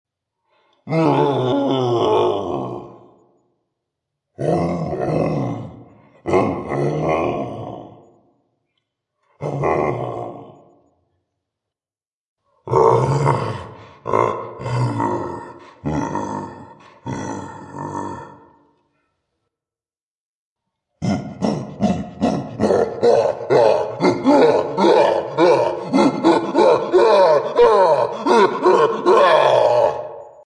Download Gorilla sound effect for free.
Gorilla